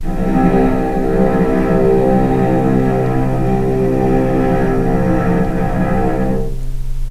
vc_sp-C2-pp.AIF